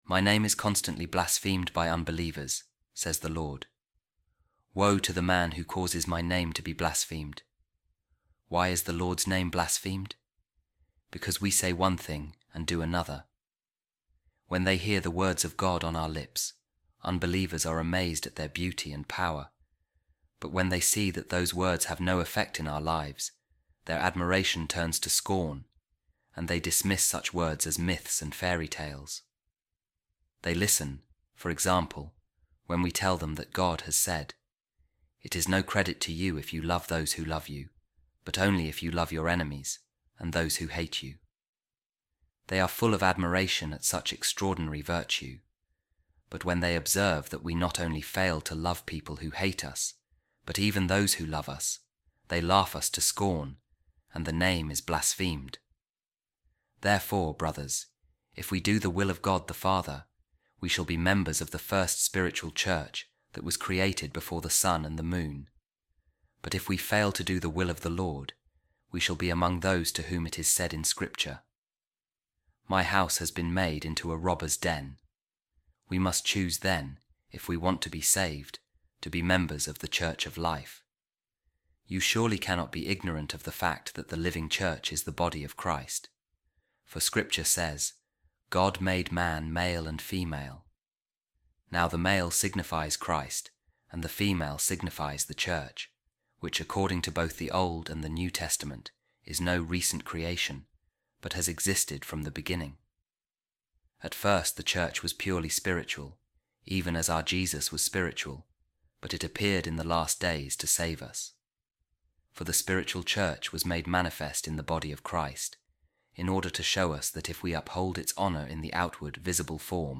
A Reading From A Homily Of A Second-Century Author | The Living Church Is The Body Of Jesus Christ